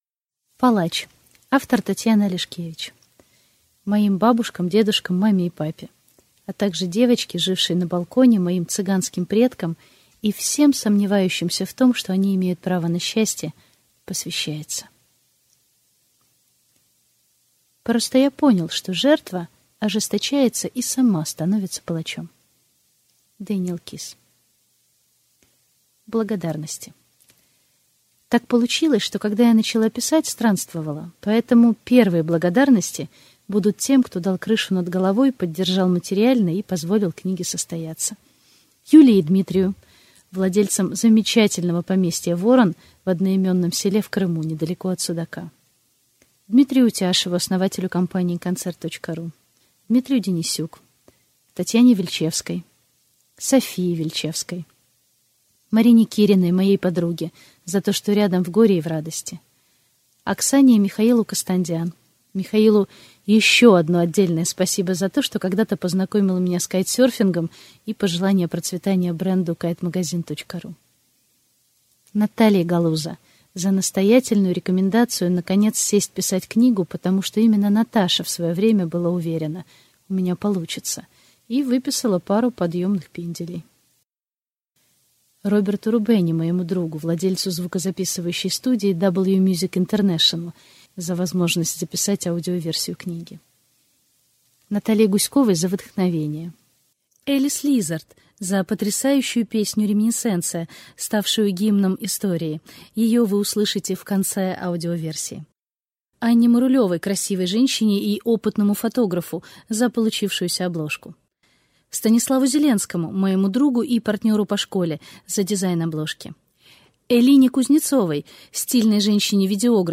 Аудиокнига Палач | Библиотека аудиокниг